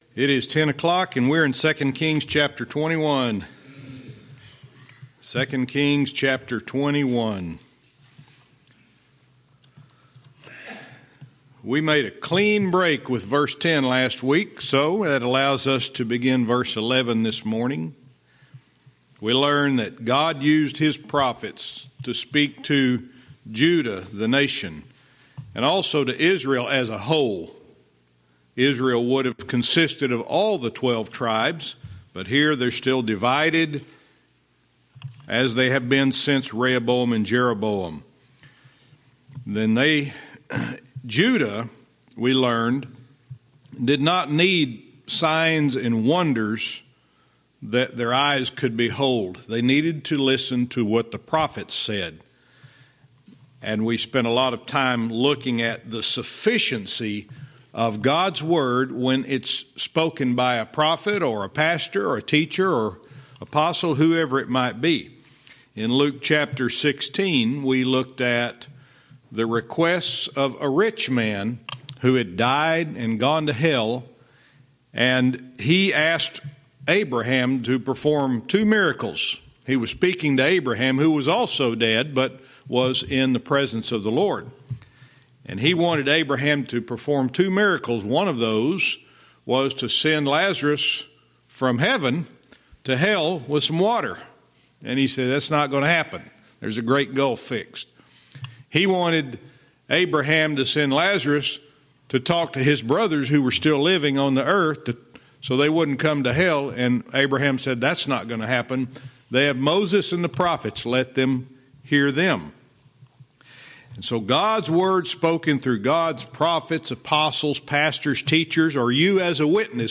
Verse by verse teaching - 2 Kings 21:11